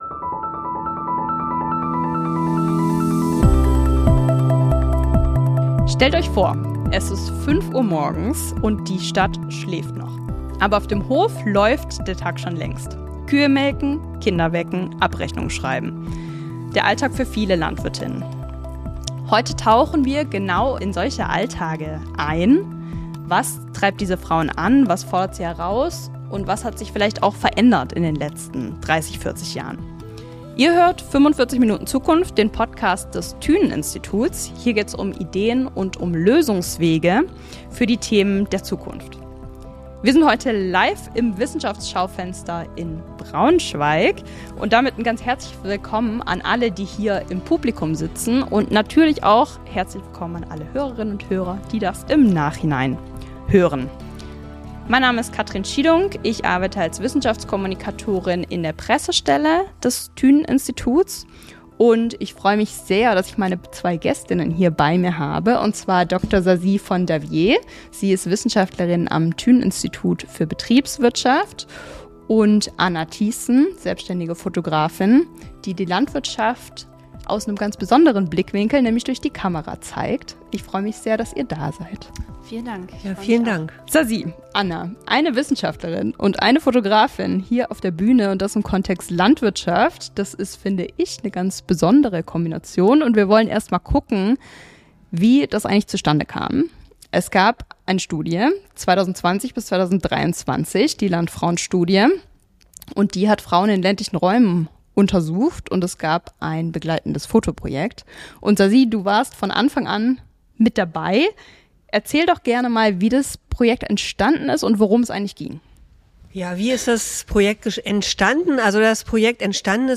LIVE: Mehr als Herd und Hof – Wie leben Landwirtinnen heute?